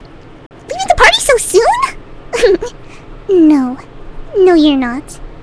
*giggle* Leaving the party so soon? No. No you're not~.wav